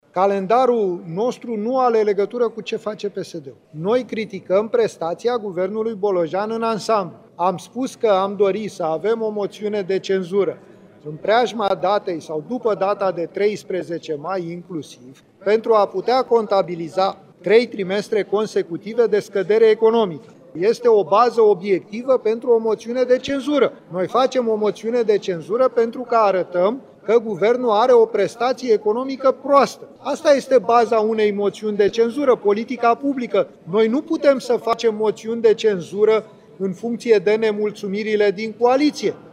„Calendarul nostru nu are legătură cu ce face PSD. Nu ne raportăm în niciun fel la certurile din coaliție, noi criticăm prestația Guvernului Bolojan în ansamblu. Am spus că ne-am dori să avem o moțiune de cenzură în preajma datei sau după data de 13 mai, inclusiv, pentru a putea contabiliza trei trimestre consecutive de scădere economică. Vom avea trei trimestre de scădere economică, iar acesta este un temei obiectiv pentru o moțiune de cenzură. Noi facem o moțiune de cenzură pentru că arătăm că Guvernul are o prestație economică proastă, că nu mai este creștere economică, că avem scădere economică, scăderea veniturilor populației și înghețarea pensiilor. Aceasta este baza unei moțiuni de cenzură, politica publică. Noi nu putem face moțiuni de cenzură în funcție de nemulțumirile din coaliție”, a declarat liderul senatorilor AUR, Petrișor Peiu, la Parlament, întrebat despre depunerea moțiunii de cenzură împotriva Guvernului Bolojan.